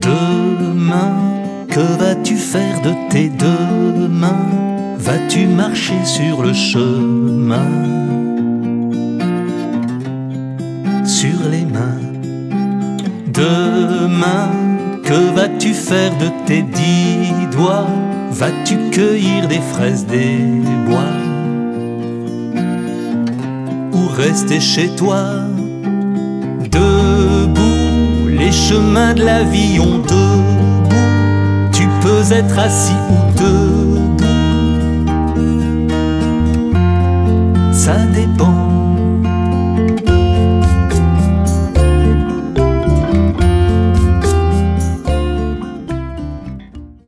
Troisième CD pour enfants